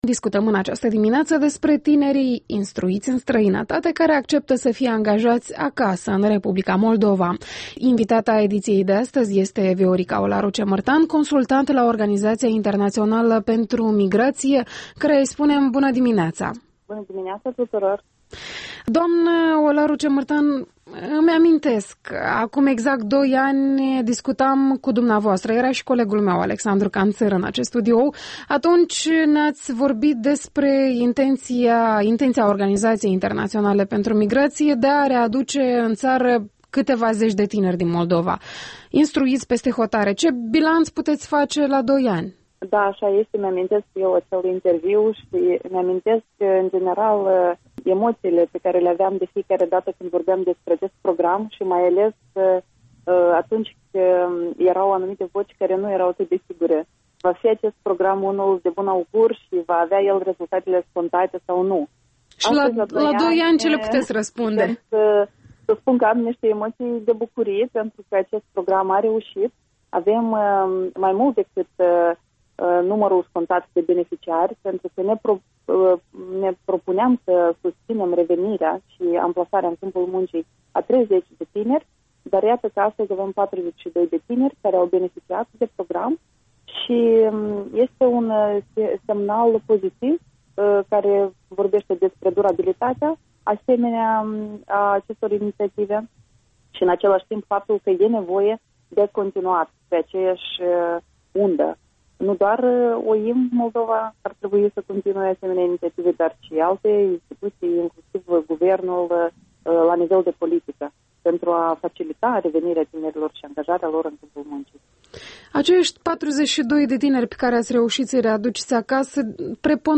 Interviul dimineții la REL